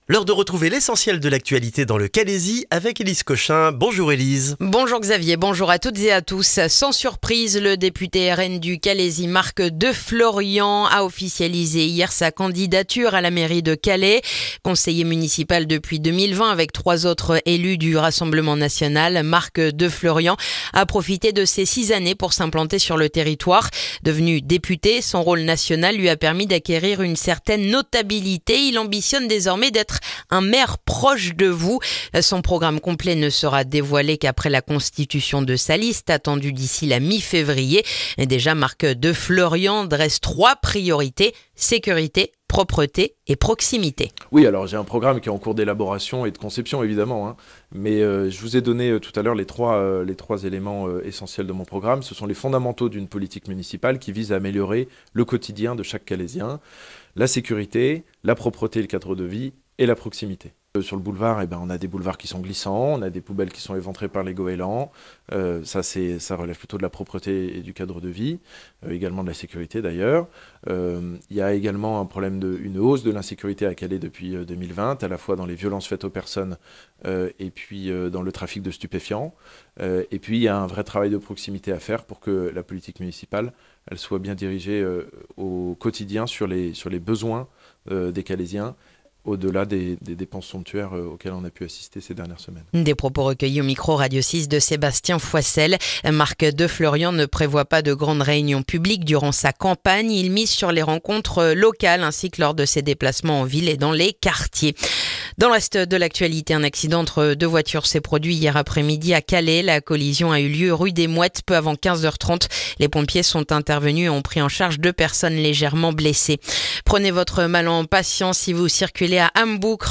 Le journal du jeudi 27 novembre dans le calaisis